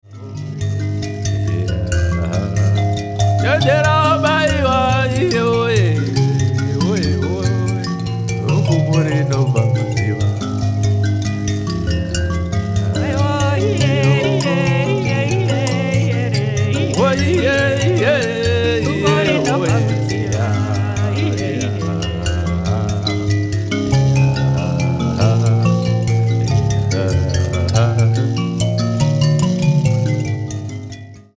it was recorded in an incredible recording studio
nemakonde tuning